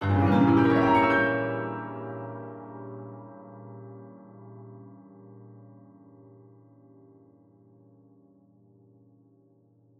Index of /musicradar/gangster-sting-samples/Chord Hits/Piano
GS_PiChrdTrill-Gdim.wav